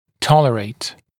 [‘tɔləreɪt][‘толэрэйт]выносить, выдерживать, переносить